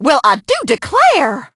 piper_hurt_01.ogg